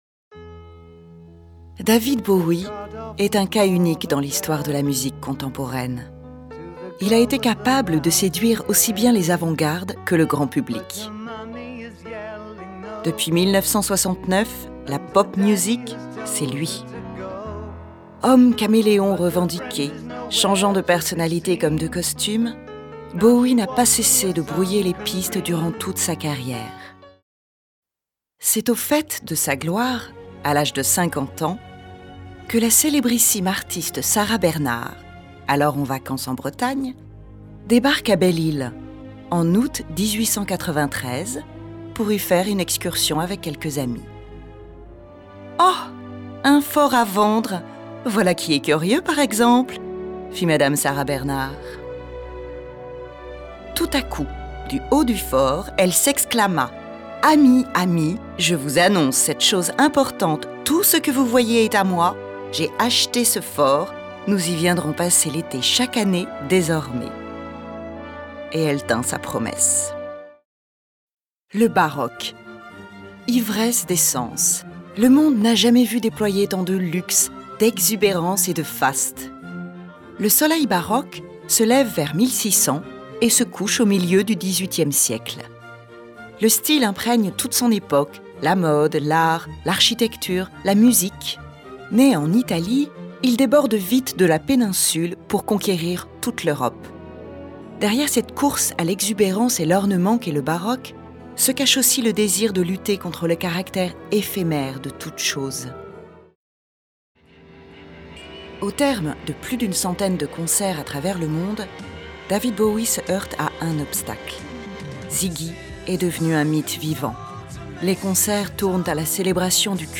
Bande démo voix
30 - 55 ans - Mezzo-soprano